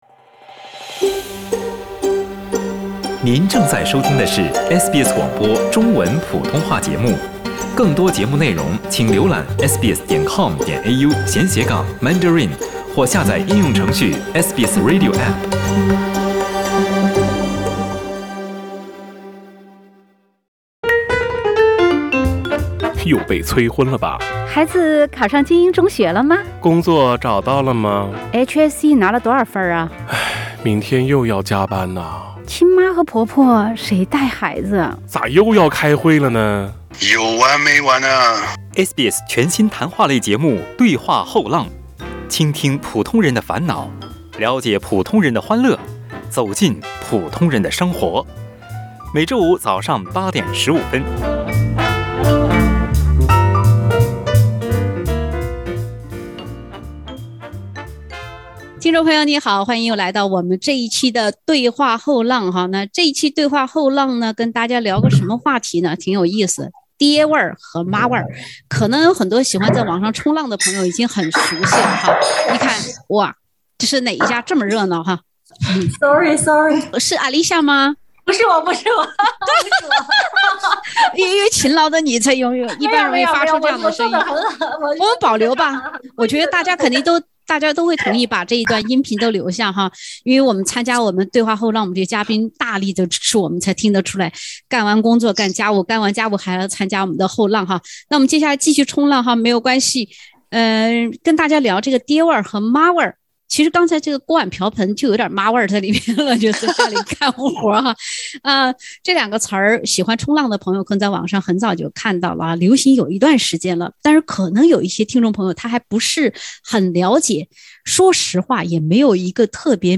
（点击封面图片，收听轻松谈话）